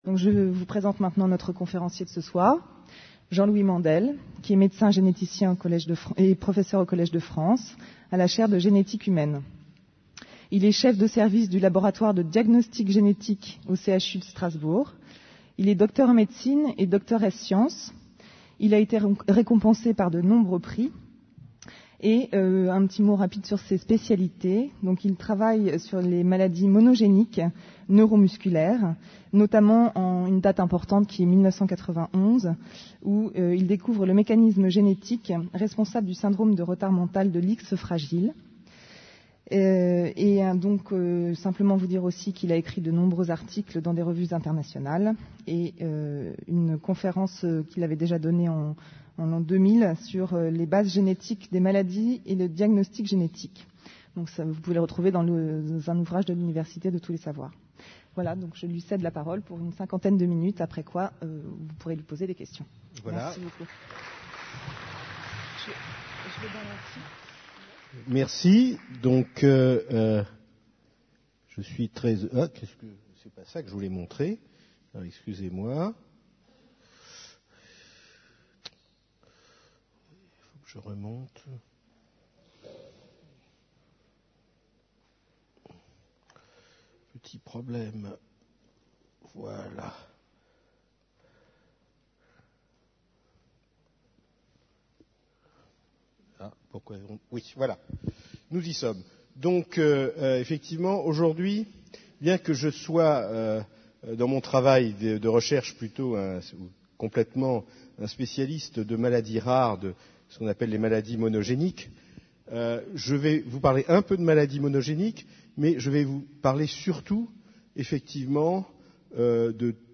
Une conférence du cycle : Qu'est ce que la vie ? Où en est la connaissance du génome ?